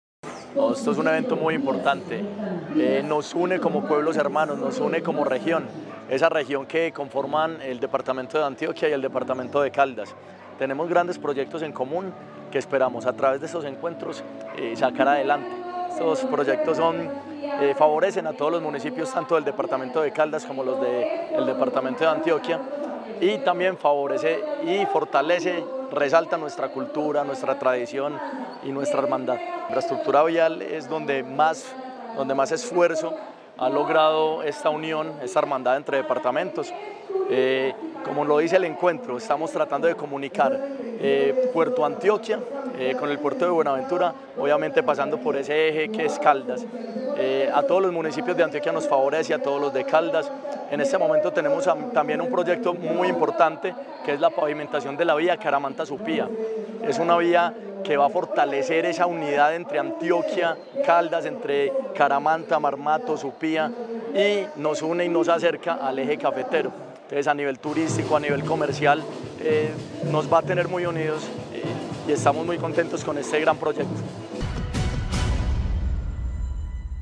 Juan Esteban Correa, alcalde de Caramanta (Antioquia)